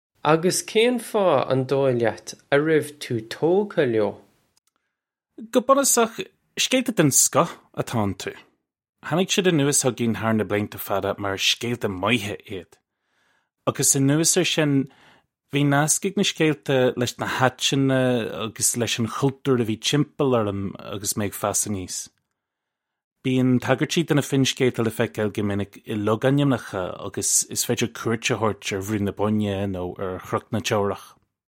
Agallamh le Seanchaí mír 2